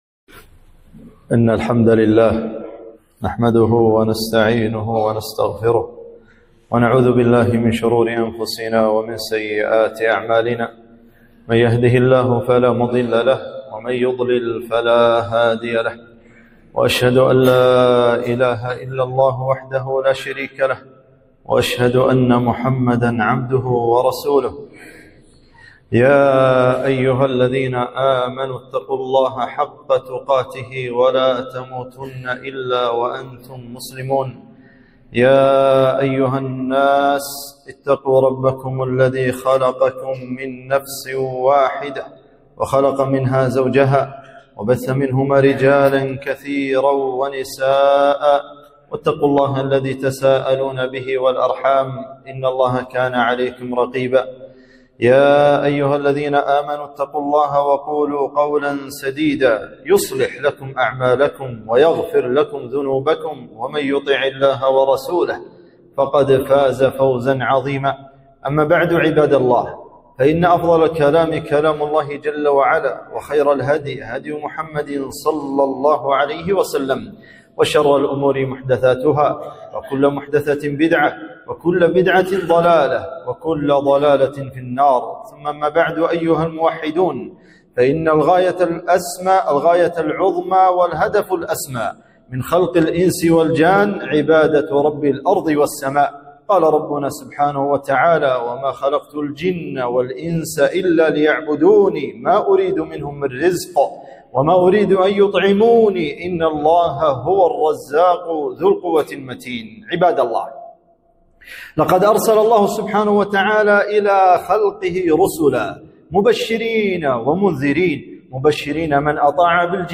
خطبة - سر السعادة بإفراد الله بالعبادة